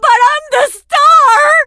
diva_die_vo_01.ogg